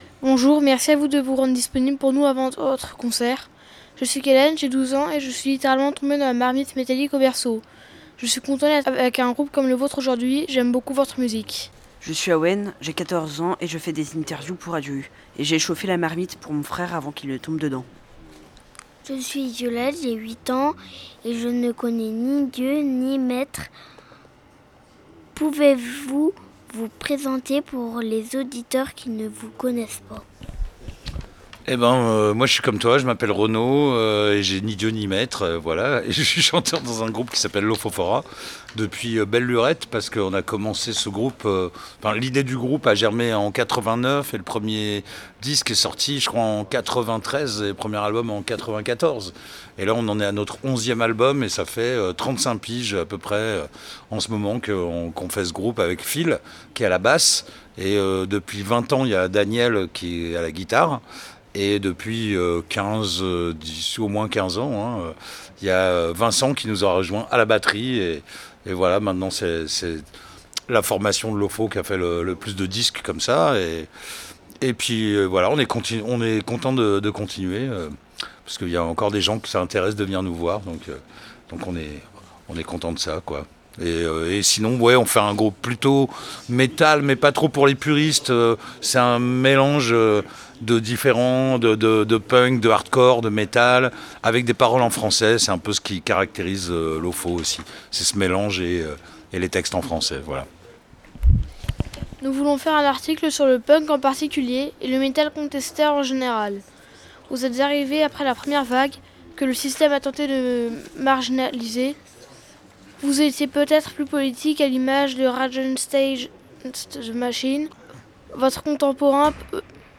Les Petits Metalleux ont eu la chance de participer à la première édition du KREIZ Y FEST d’automne à LESNEVEN. A cette occasion, ils ont pu rencontrer et interviewer LOFOFORA.